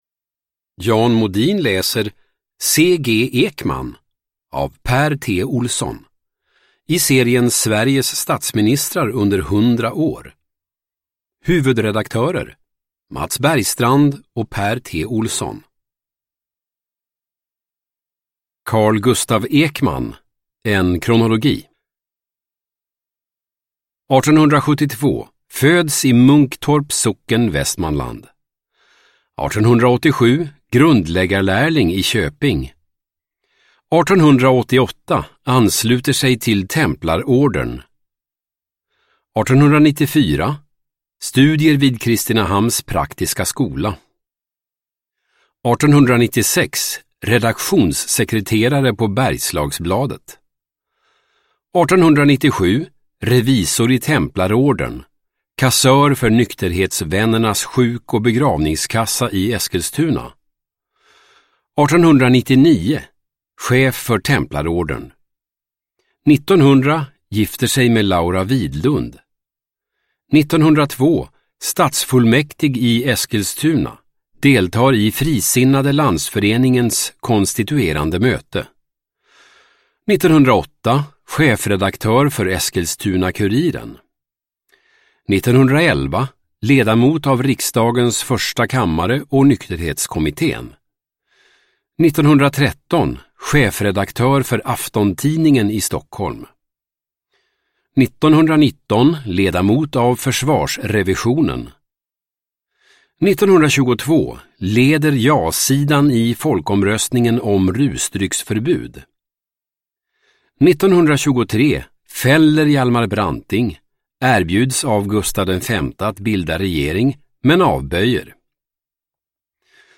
Sveriges statsministrar under 100 år : C G Ekman – Ljudbok – Laddas ner